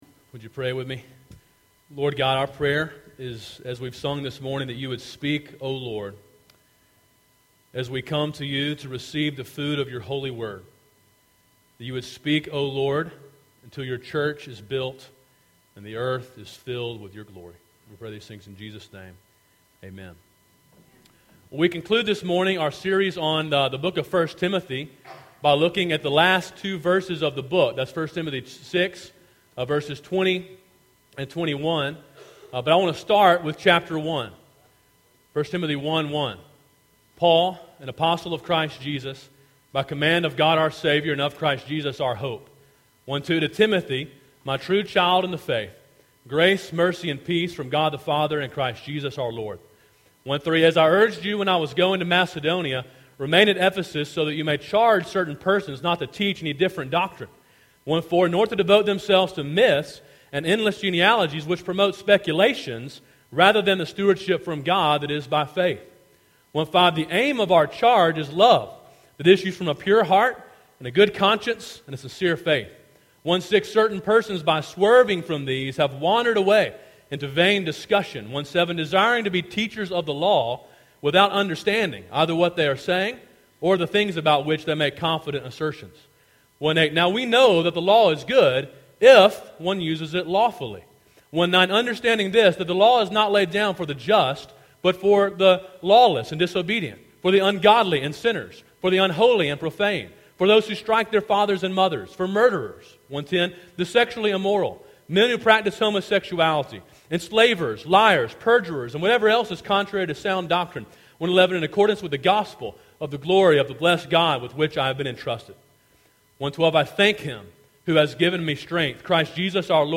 Quotation of 1 Timothy & Sermon “Guard the Deposit” (1 Timothy 6:20-21)